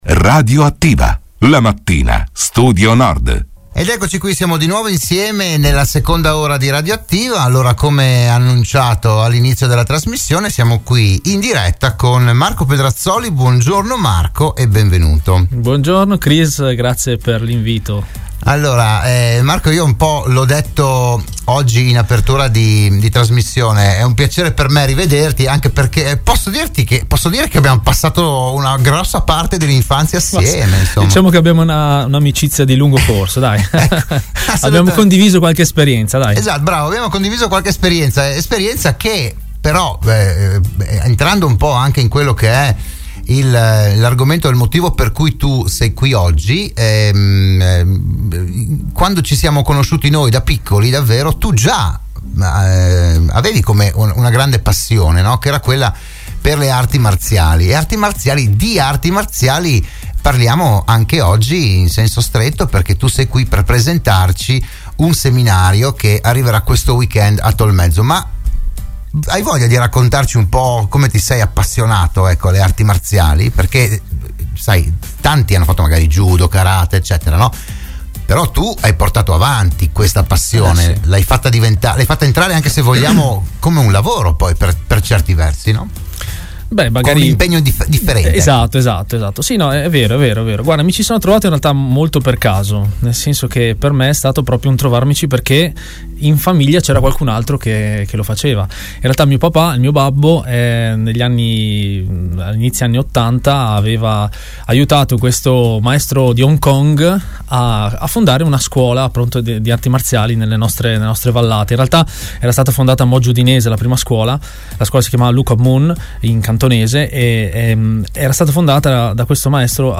RadioAttiva“, la trasmissione del mattino di Radio Studio Nord